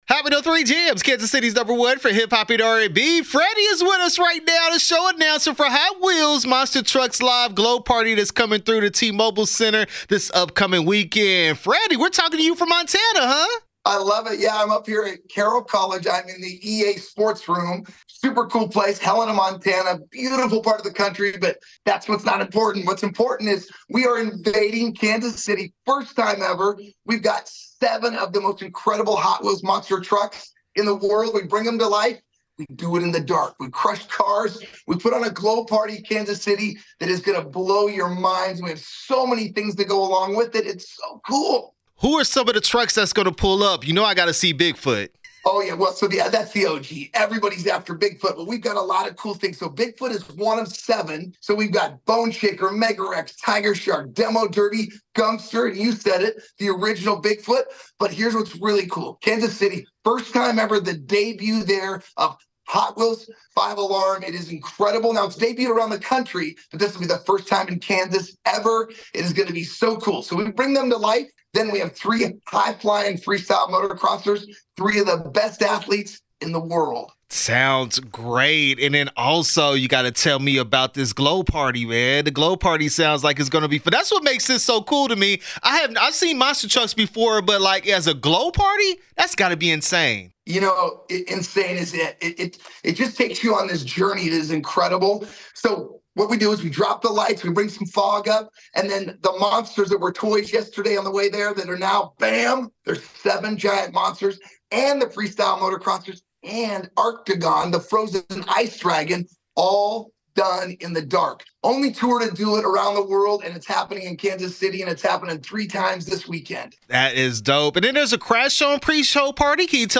Hot Wheels Monster Trucks Live Glow Party interview 10/4/23